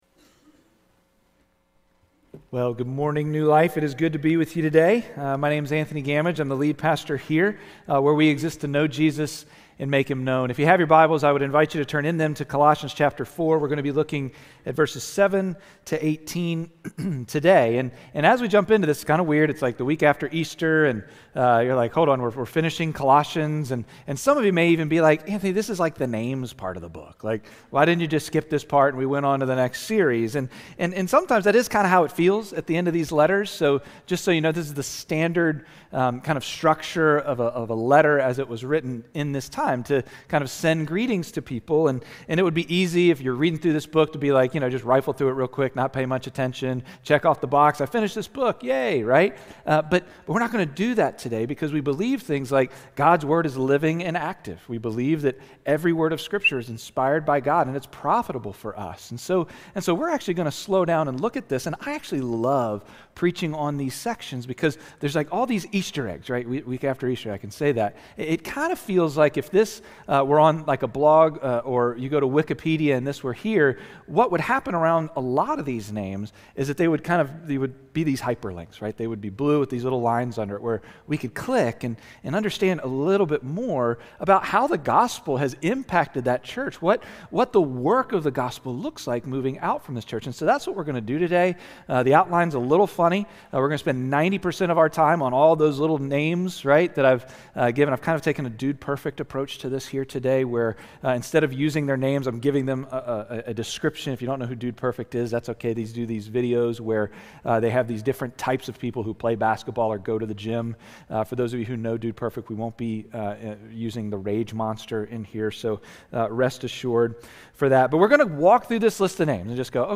Sermon-42725.mp3